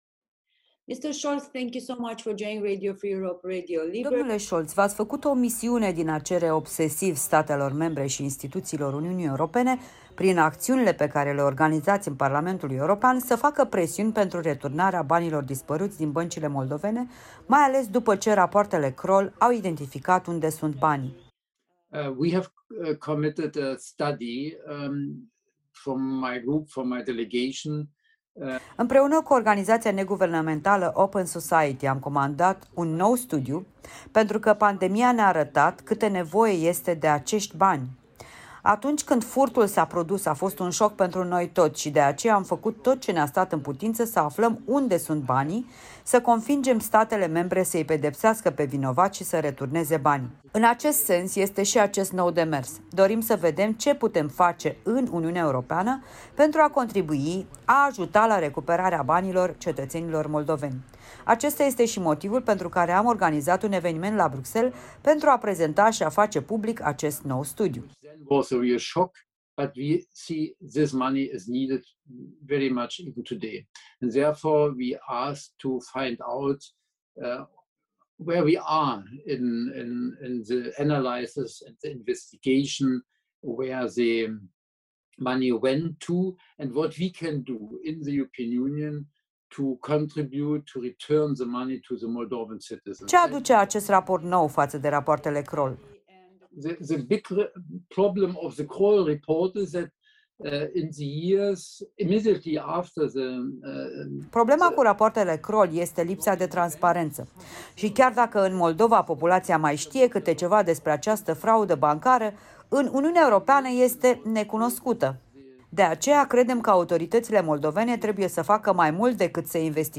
Helmut Scholz, membru al Delegației UE- R.Moldova
„Chișinăul trebuie să discute în mod activ cu partenerii europeni din statele unde se știe că se află banii, și să le ceară returnarea acestora. Iar dacă nu se întâmplă nimic, trebuie să acționeze în justiție, la nivel international,” afirmă într-un interviu cu Europa Liberă europarlamentarul german.